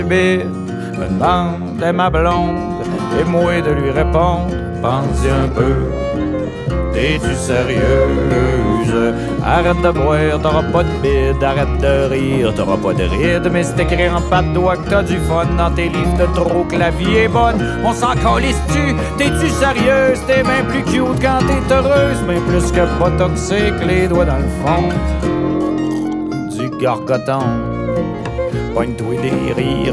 Genre: Folk